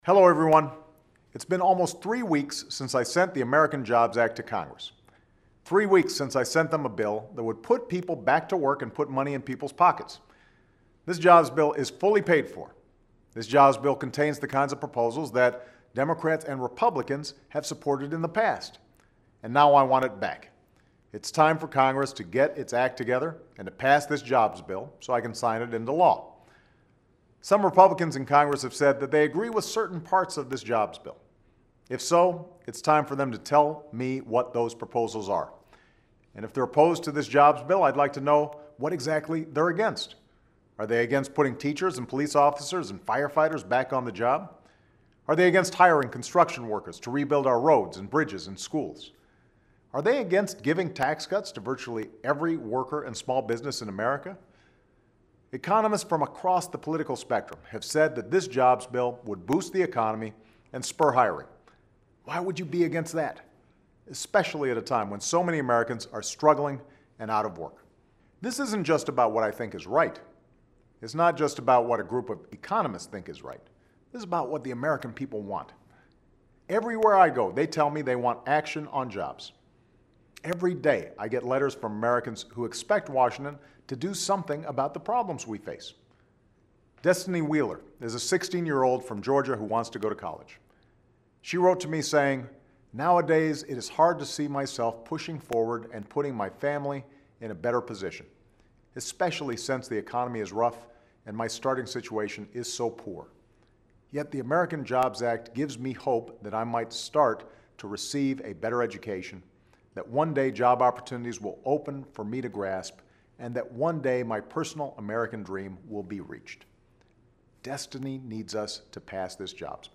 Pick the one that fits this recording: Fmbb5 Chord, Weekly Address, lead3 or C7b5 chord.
Weekly Address